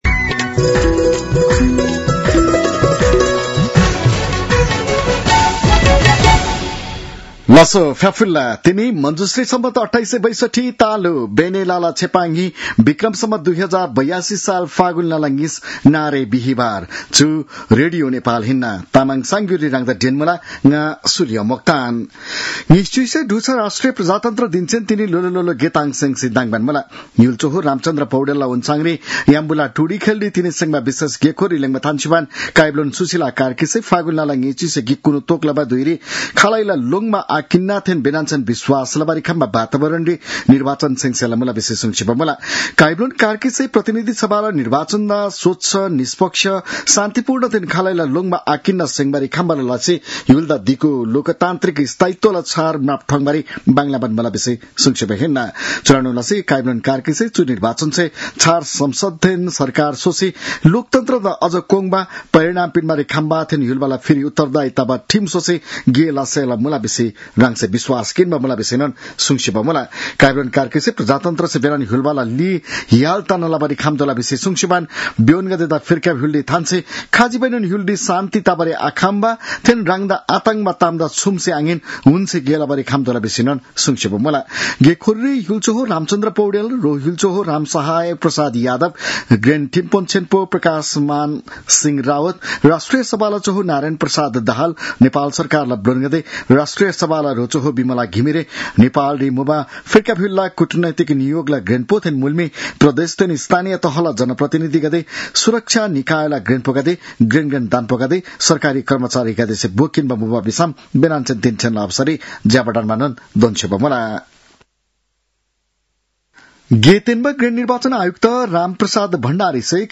तामाङ भाषाको समाचार : ७ फागुन , २०८२